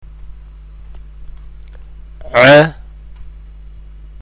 ഉച്ചാരണം (പ്ലേ ബട്ടണ്‍ അമര്‍ത്തുക)